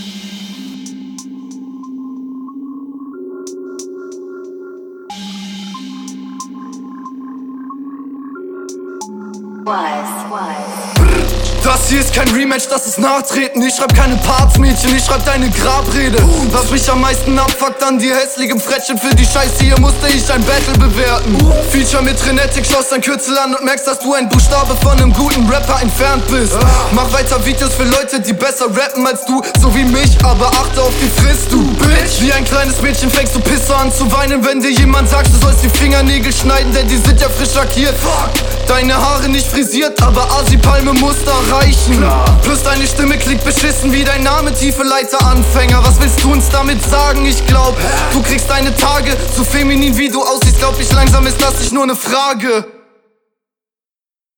Das hier hat schon viel mehr Battlerapcharakter, Beat würde ich auch nehmen.
Coole Flowvariationen, aber nicht so sauber umgesetzt.